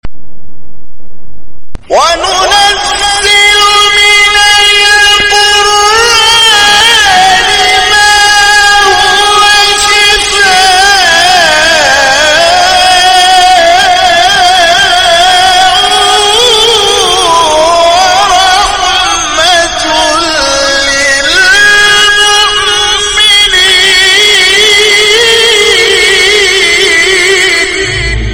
شبکه اجتماعی: فرازهای صوتی از تلاوت قاریان ممتاز و بین المللی کشور که به تازگی در شبکه‌های اجتماعی منتشر شده است، می‌شنوید.